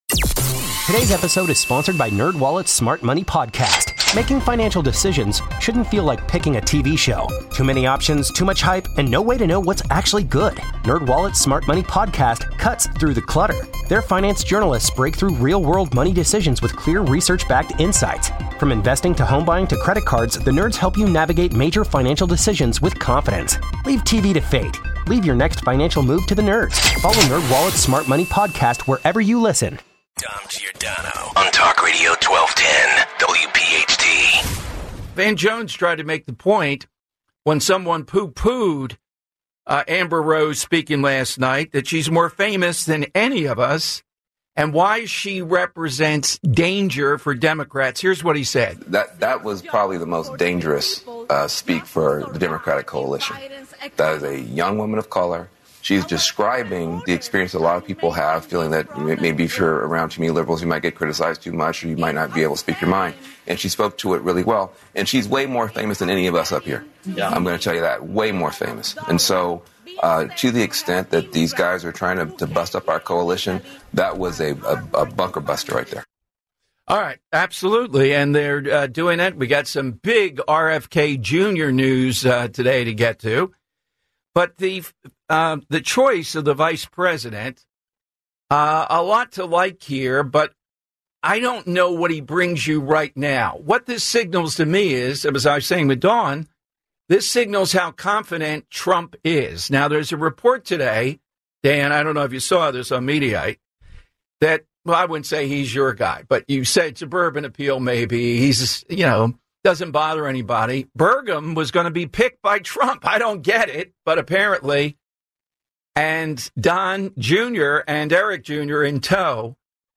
Full Hour